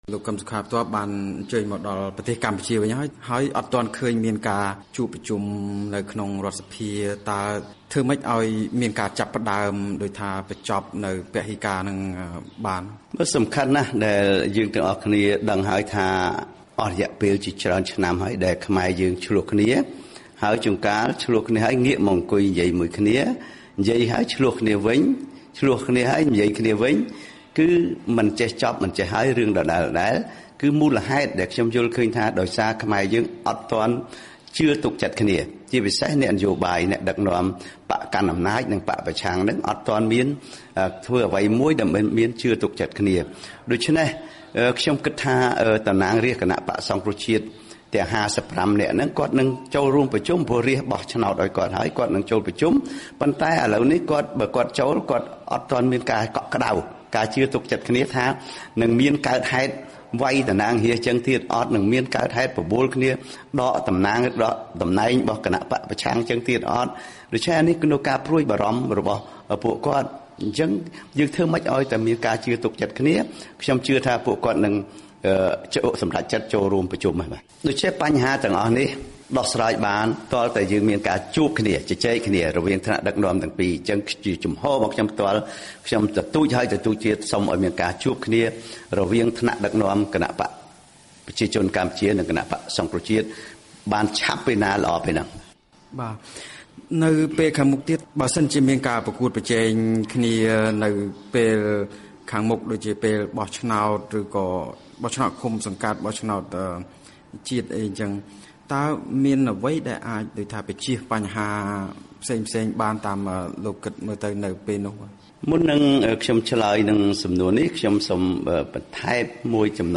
បទសម្ភាសន៍ VOA៖ ប្រធានស្តីទីគណបក្សសង្គ្រោះជាតិជំរុញឲ្យគណបក្សទាំងពីរជួបគ្នារកដំណោះស្រាយ